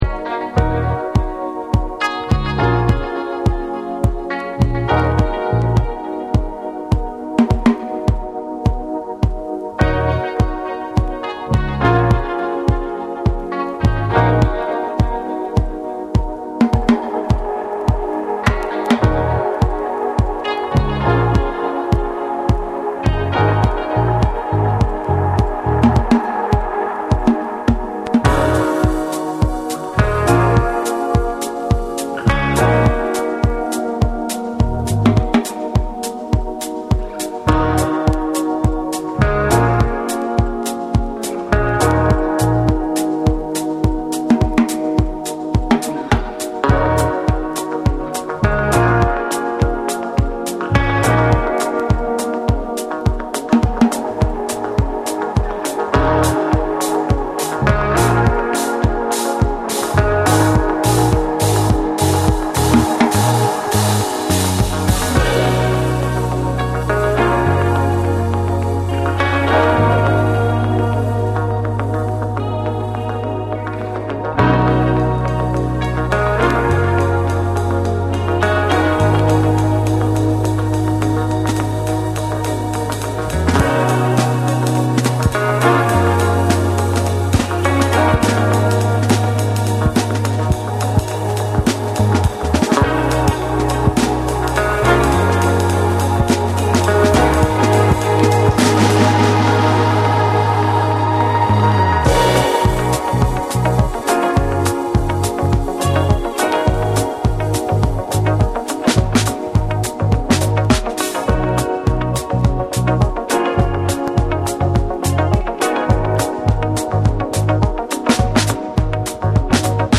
TECHNO & HOUSE / NEW RELEASE(新譜)